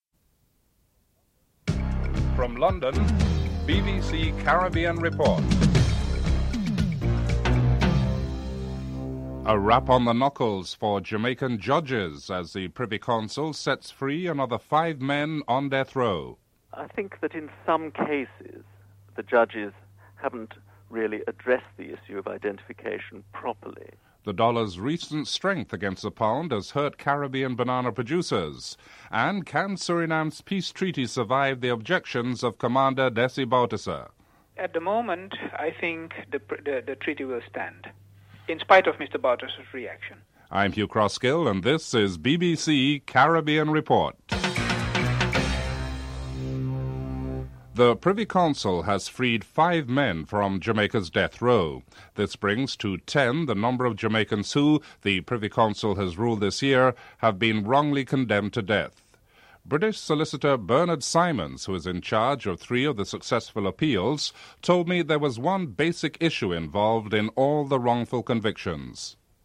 3. Financial news (07:06-08:10)